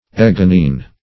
Search Result for " ecgonine" : The Collaborative International Dictionary of English v.0.48: Ecgonine \Ec"go*nine\ (?; 104), n. [Gr.